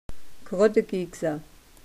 Wörterbuch der Webenheimer Mundart